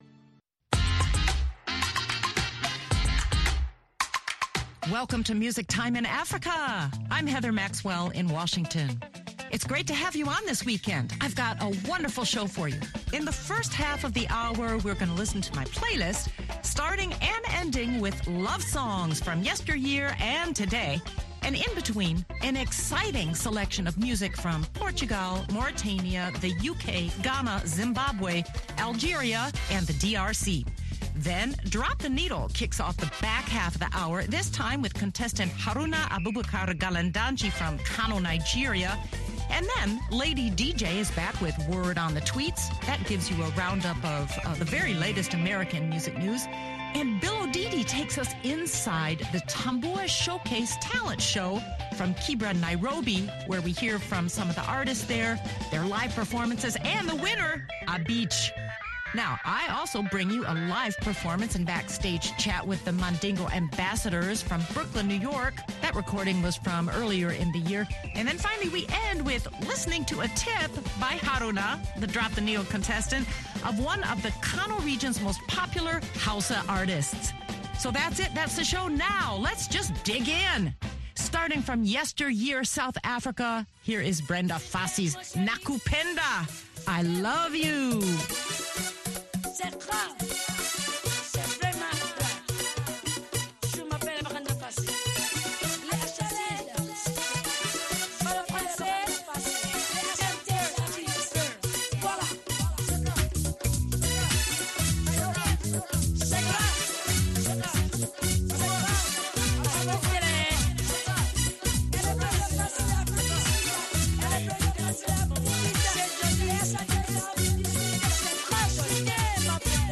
Listen to live and backstage chat with The Mandingo Ambassadors. Word on the Tweets from VOA-1 presents entertainment news from America.